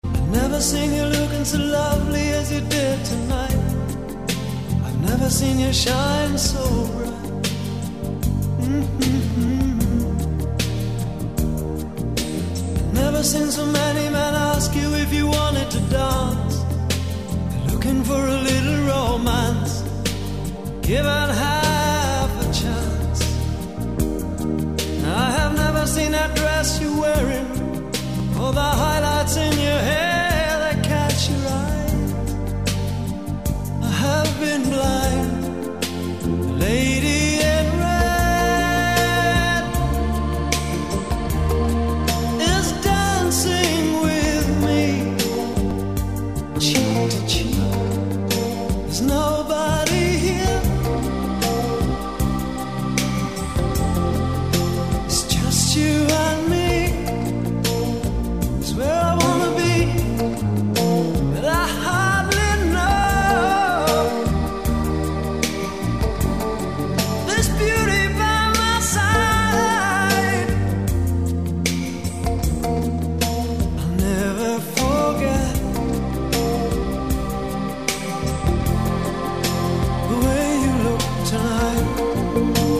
красивые
спокойные
ретро